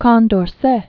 (kôn-dôr-sĕ), Marquis de.